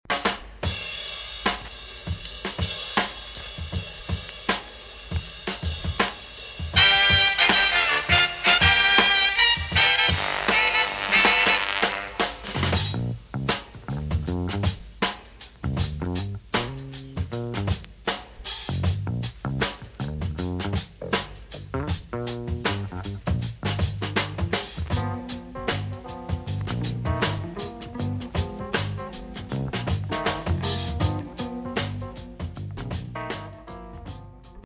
Well known drumbreak intro